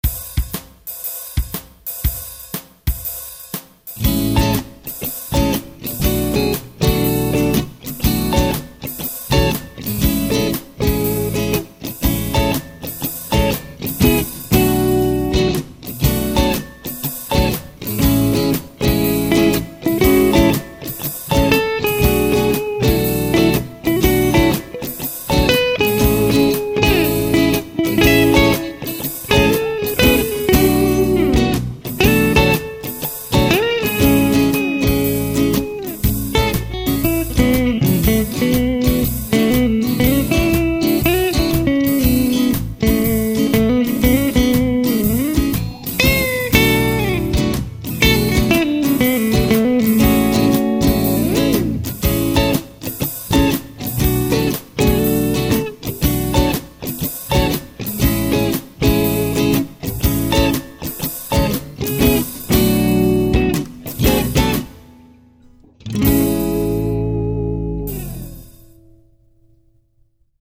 Hi Everyone I finally got around to laying down a jazzy happy birthday track for the competition ?
Birthday Jazz.mp3